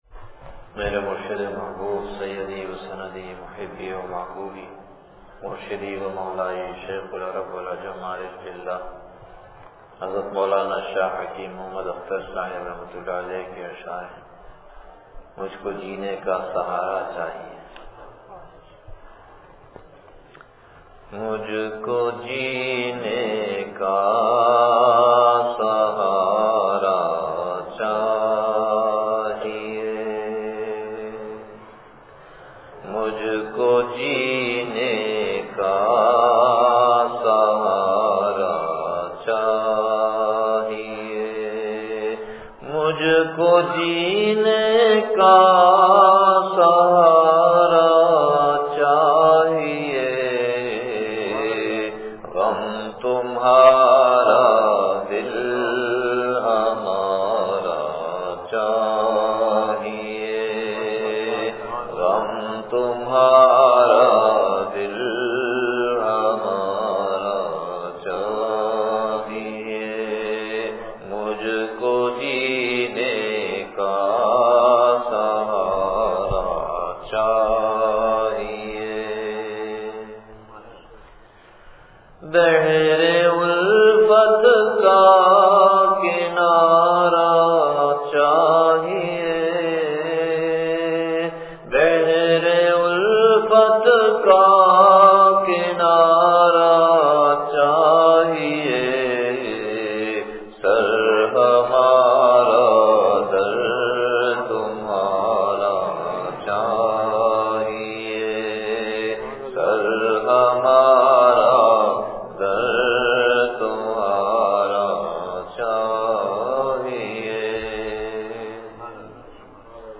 بیان بعد نماز فجر مسجد صدیقِ اکبر دنیا پور شہر